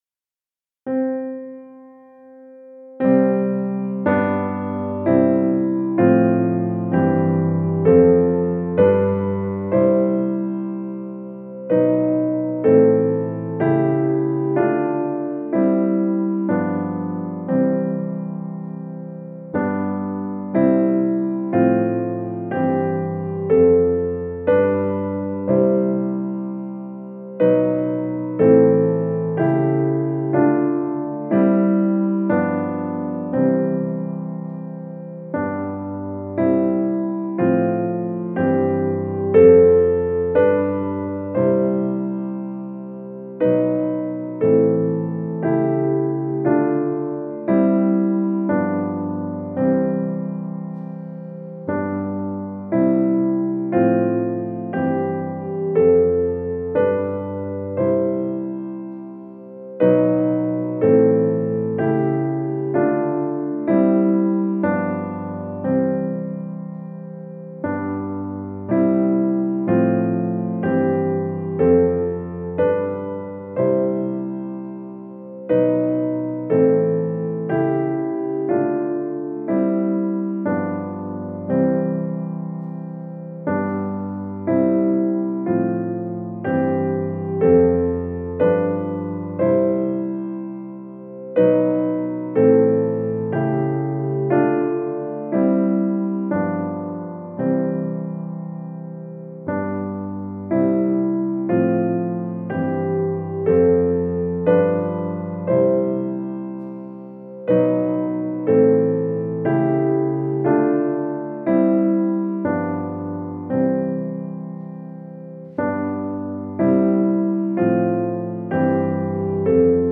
06 Tonleiter
Tonleiter im 7-Sekunden-Takt: